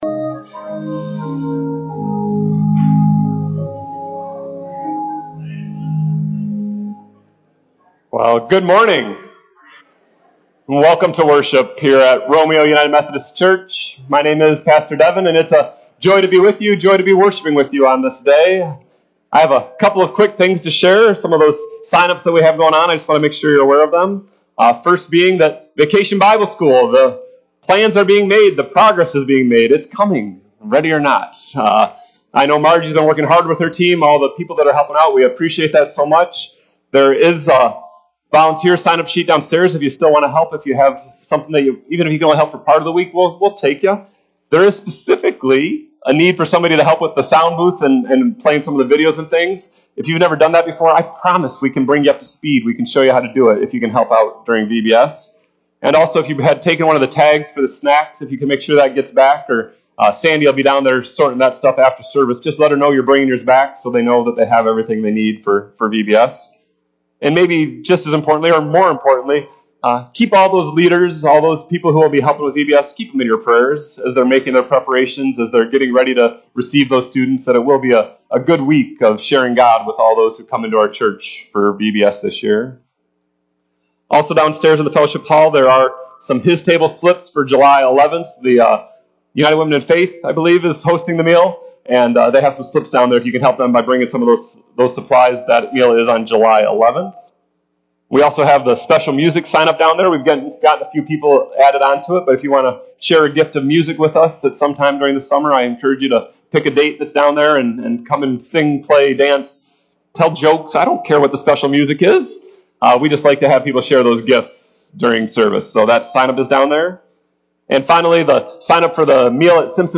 RUMC-service-July-2-2023-CD.mp3